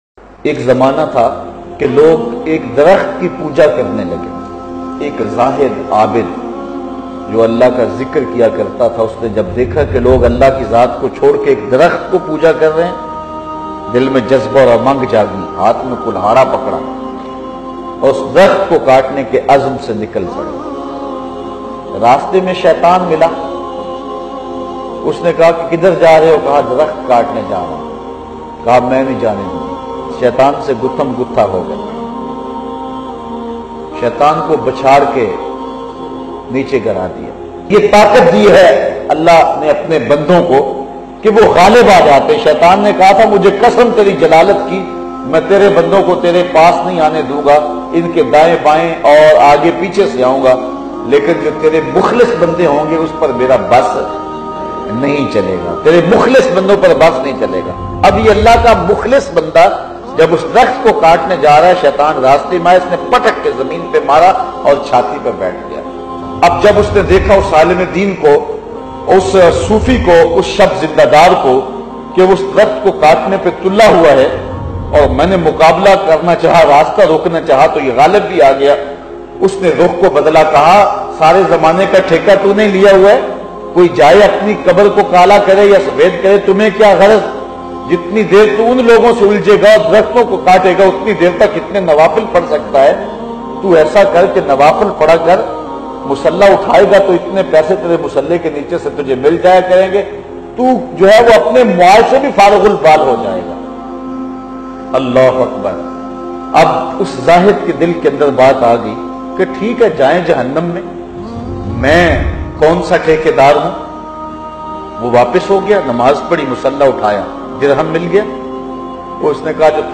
Sache dil se ibadat or lalach bayan mp3